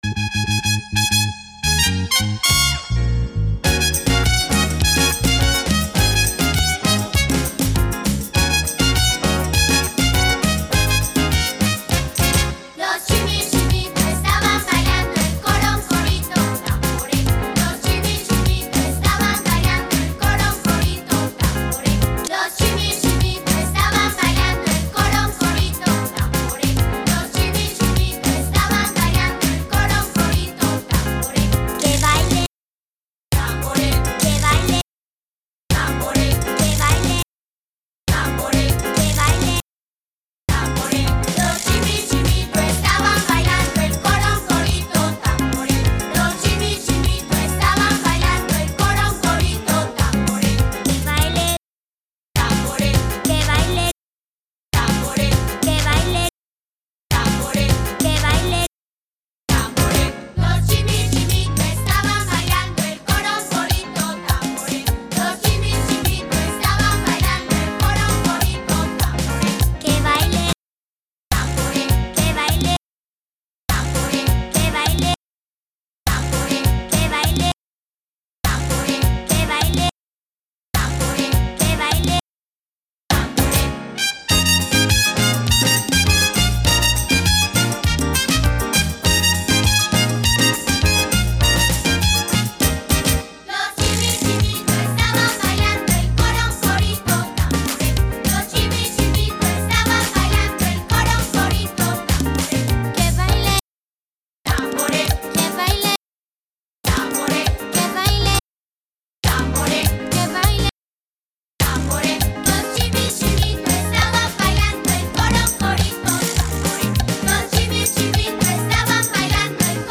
La dansa és molt animada i ens ha fet moure l’esquelet!!
A l’espai de “silenci” heu de dir el nom de qui voleu que vagi sortint al mig a dansar. Atenció, que va molt ràpid!